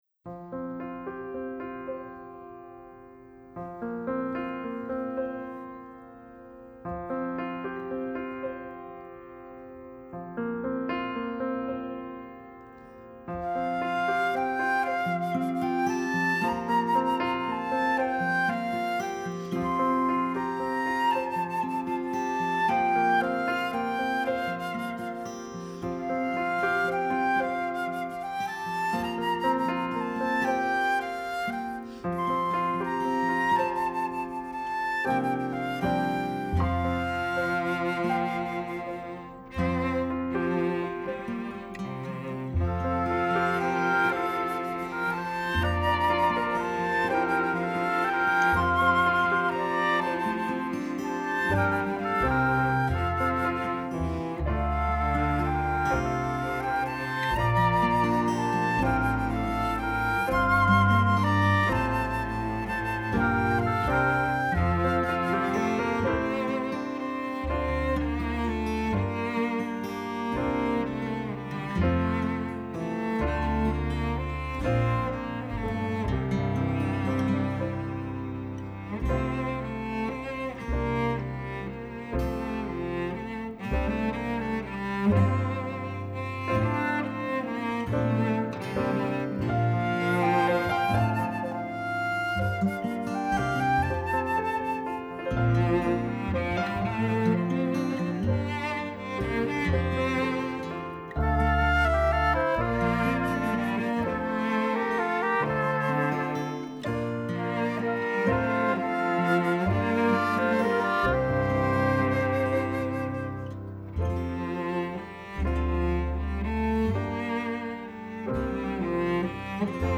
Voicing: Two-part mixed; Assembly